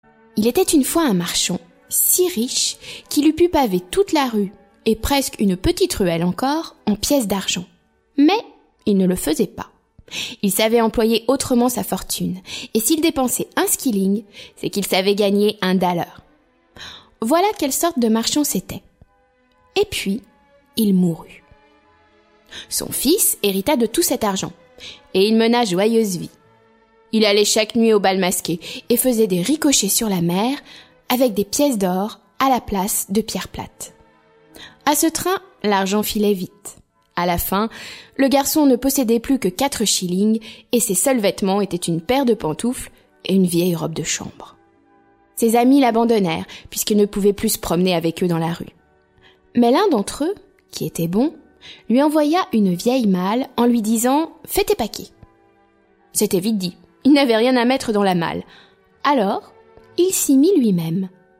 Diffusion distribution ebook et livre audio - Catalogue livres numériques
Musique : Offenbach (Contes) et Wagner (Tannhauser, Ouverture)